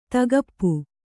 ♪ tagappu